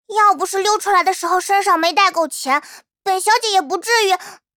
【模型】GPT-SoVITS模型编号028_女-secs
GPT-SoVITS 人声克隆